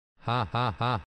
Play Nunu Haha Softer - SoundBoardGuy
Play, download and share Nunu haha softer original sound button!!!!